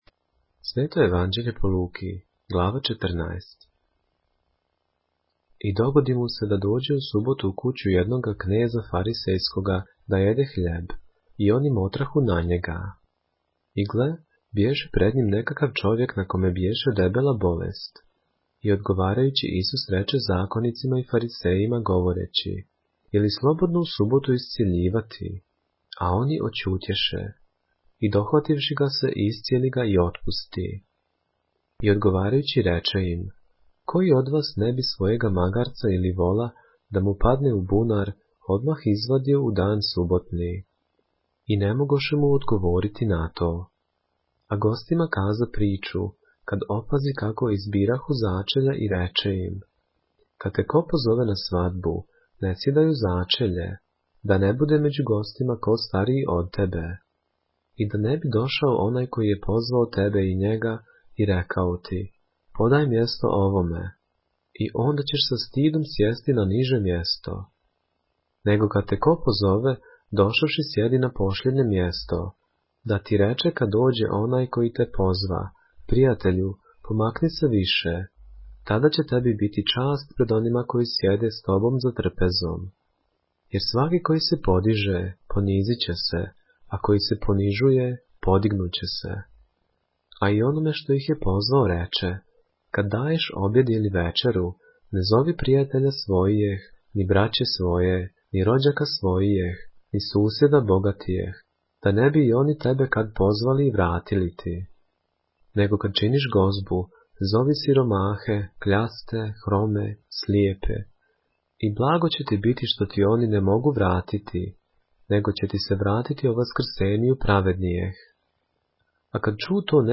поглавље српске Библије - са аудио нарације - Luke, chapter 14 of the Holy Bible in the Serbian language